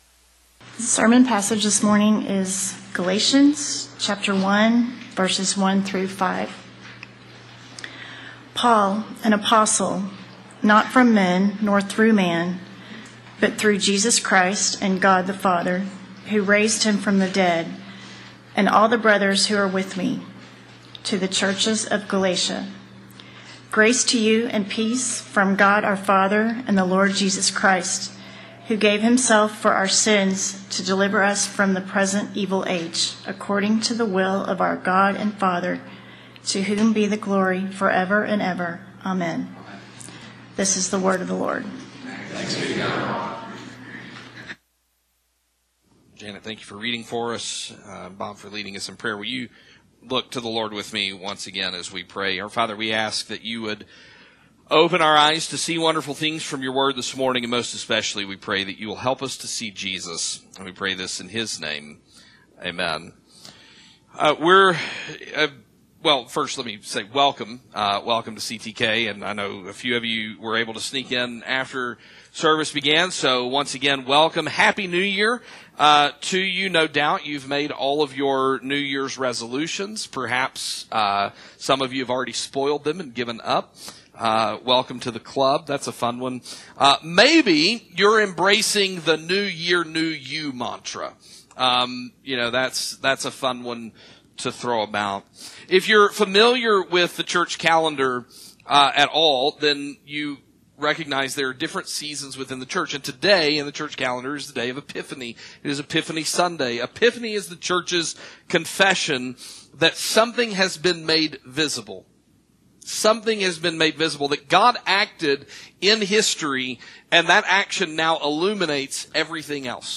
1 January 4th Sermon 36:26